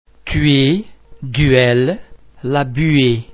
The French [u+vowel ] sound is actually the French [ u ] sound pronounced before an [ i ], [ y ], [e] or [a] but perhaps shorter and more like just a transition sound.
ui_tuer.mp3